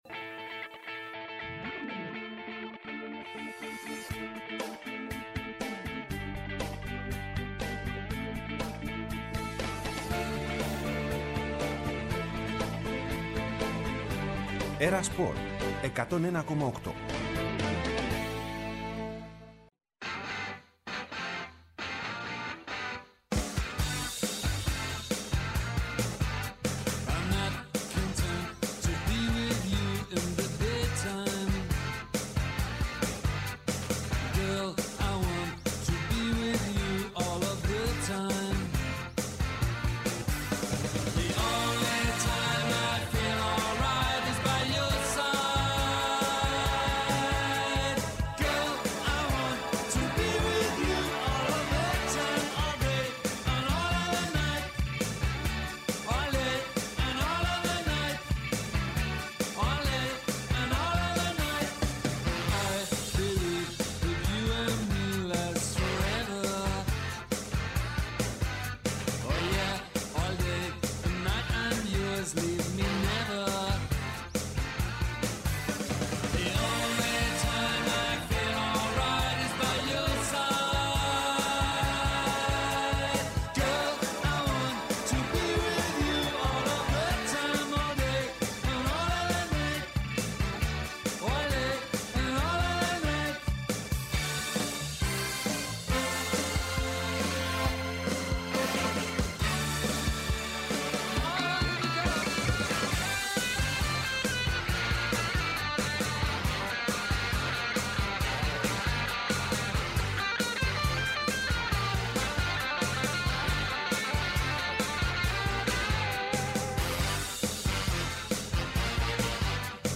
Η Κατερίνα Στικούδη ήταν καλεσμένη στην ΕΡΑ ΣΠΟΡ και στην εκπομπή «Τελευταία Σελίδα»